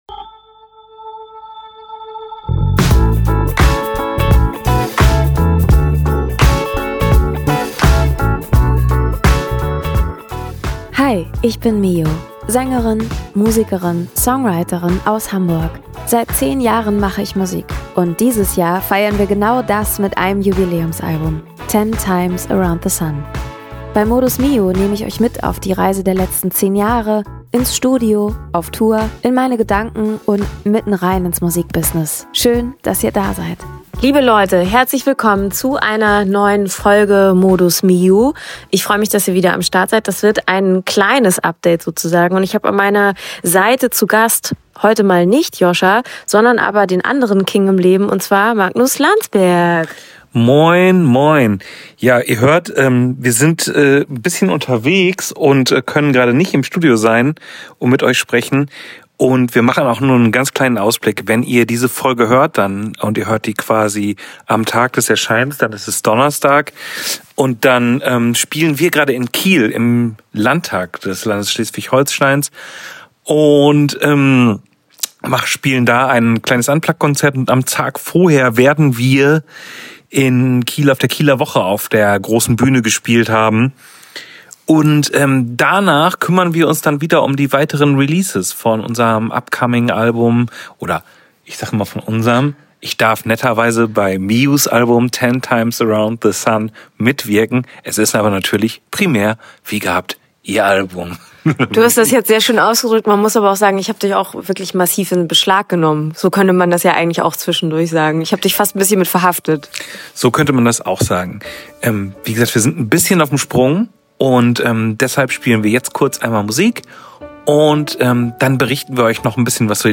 Kurzes Quickie Update von unterwegs, nächste Woche wieder ausführlicher 😘